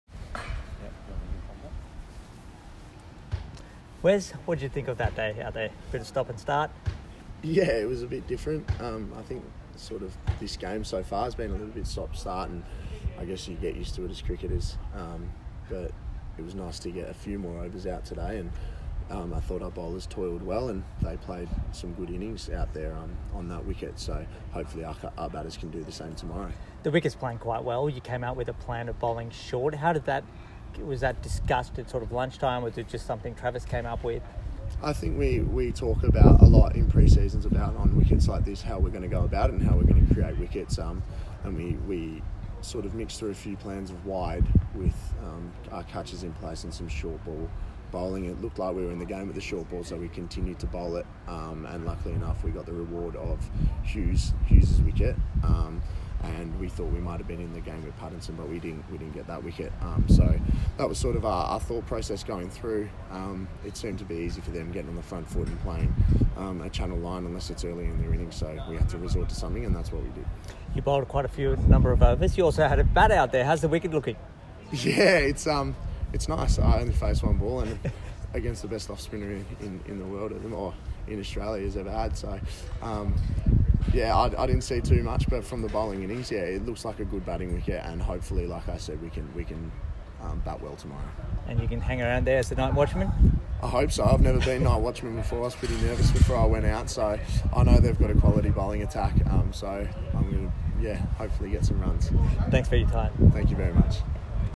Wes Agar speaks after he took 2-69